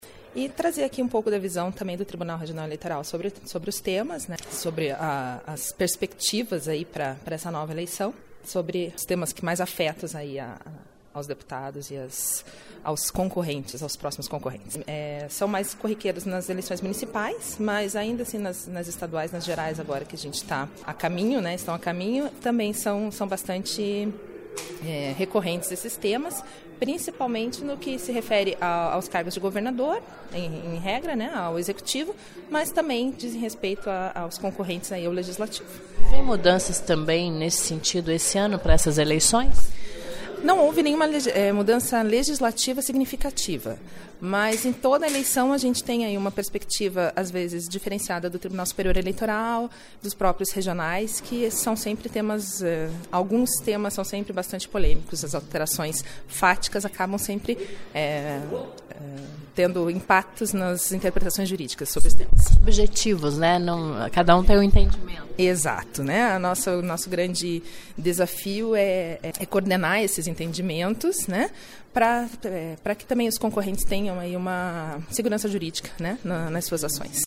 durante o Ciclo de Debates promovido pela Escola do  Legislativo em  parceria com a Comissão de Direito Eleitoral da OAB/PR.